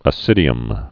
(ə-sĭdē-əm)